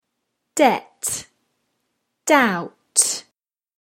debt-doubt.mp3